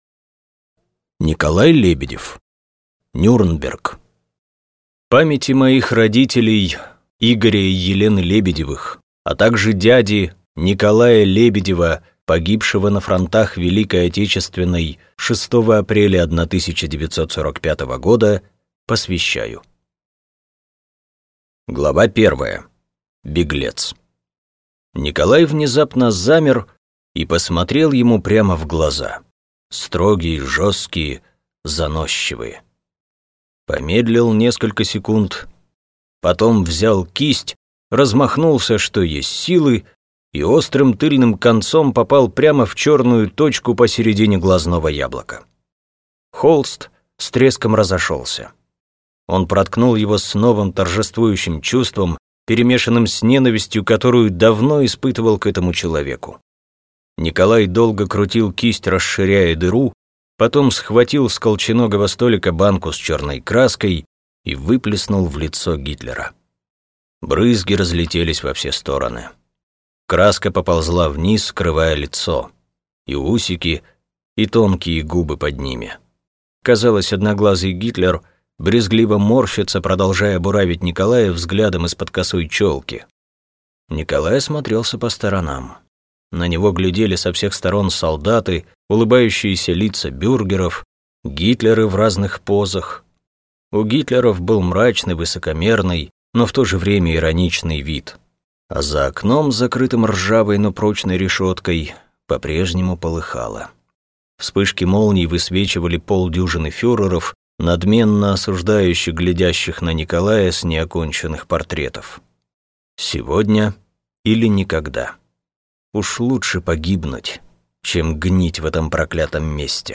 Аудиокнига Нюрнберг | Библиотека аудиокниг